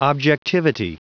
Prononciation du mot objectivity en anglais (fichier audio)
Prononciation du mot : objectivity